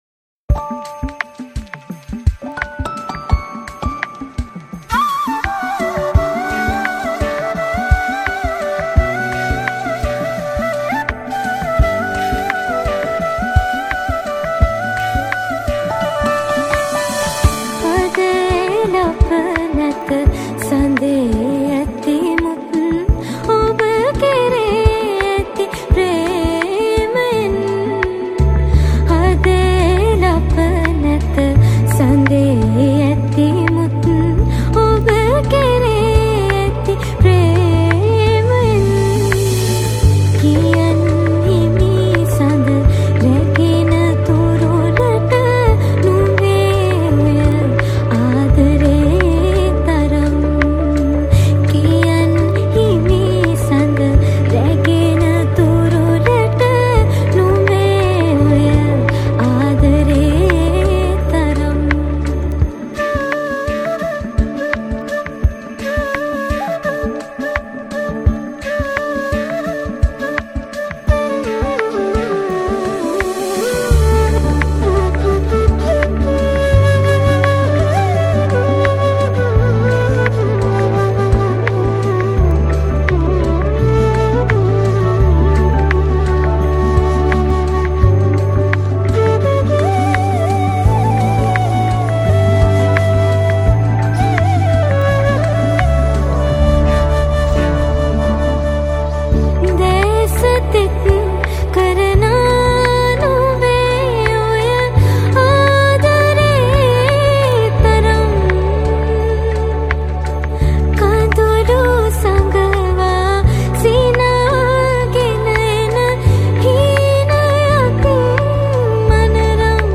Guitar
Flute